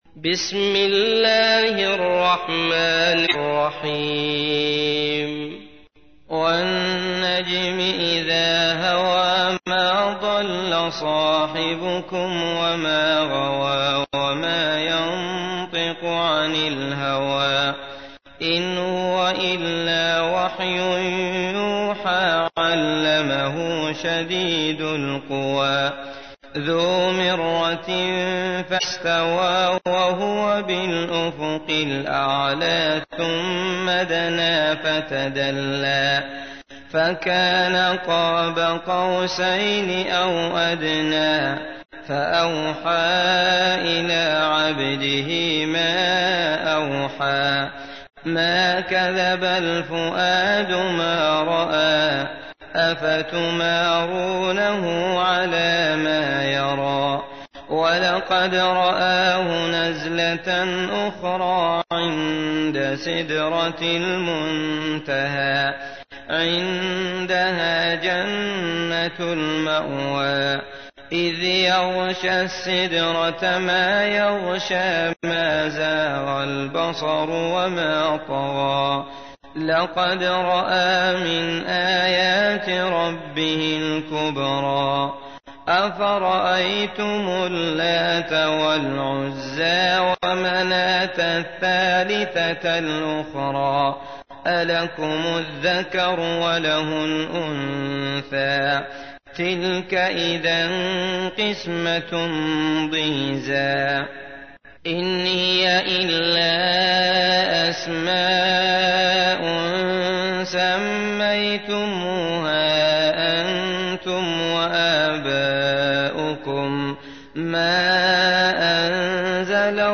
تحميل : 53. سورة النجم / القارئ عبد الله المطرود / القرآن الكريم / موقع يا حسين